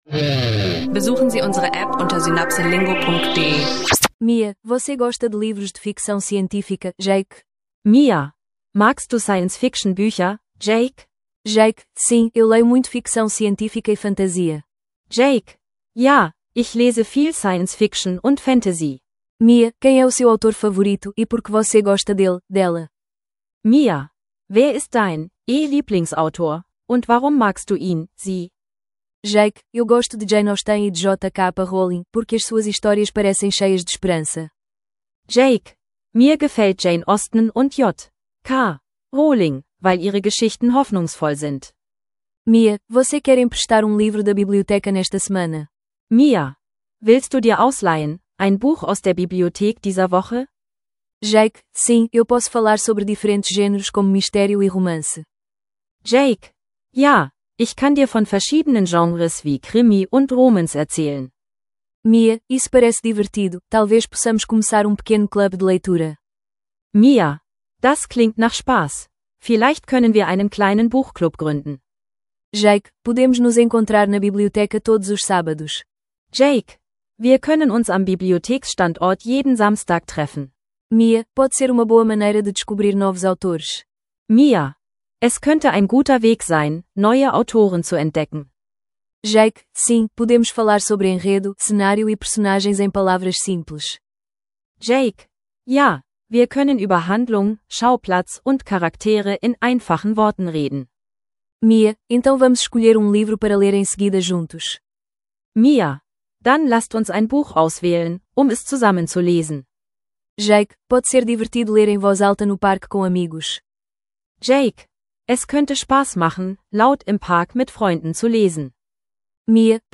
SynapseLingo Portugiesisch lernen – Bücher und Autoren: Kurze Dialoge zum Portugiesisch lernen
Kurze Dialoge über Bücher, Autoren und Gattungen—lerne Portugiesisch mit alltäglichen Gesprächen.